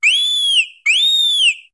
Cri de Plumeline Style Pom-Pom dans Pokémon HOME.
Cri_0741_Pom-Pom_HOME.ogg